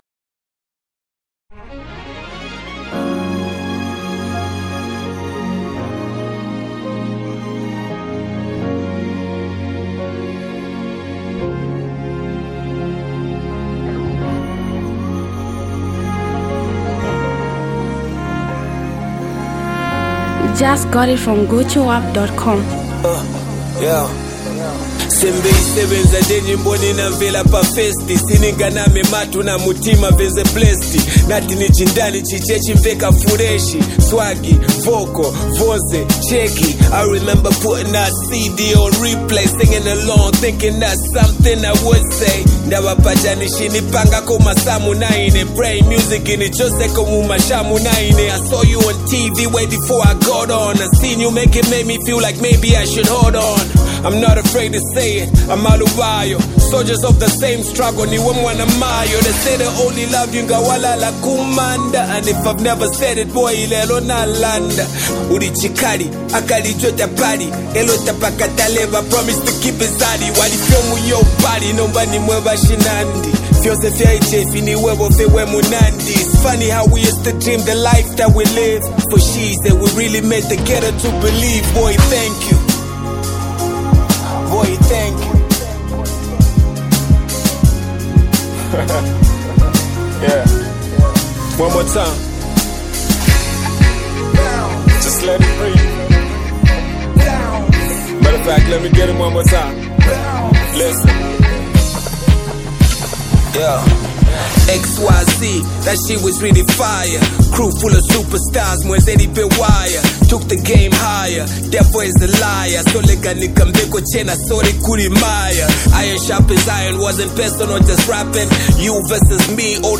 melodic sound
ghetto Hip-Hop vibing genre